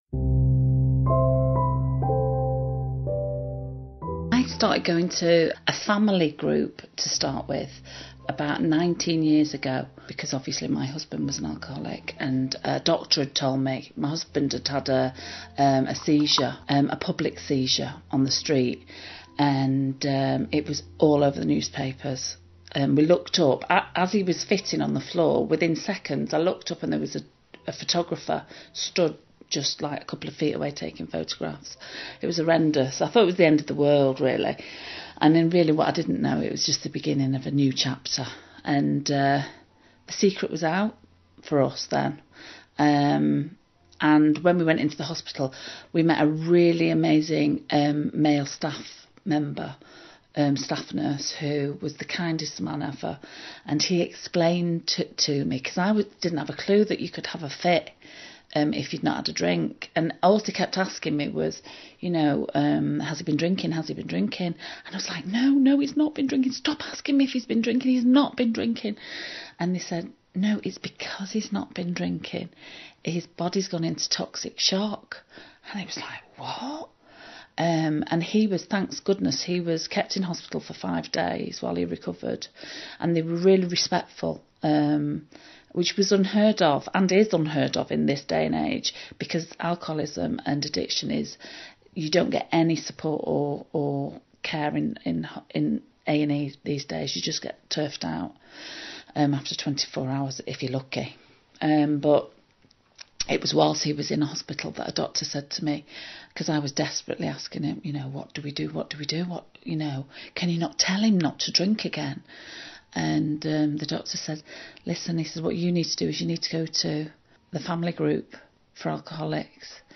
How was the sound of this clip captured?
attends an alcoholic support group to bring you the conversations you wouldn't otherwise get to hear